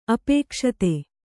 ♪ apēkṣate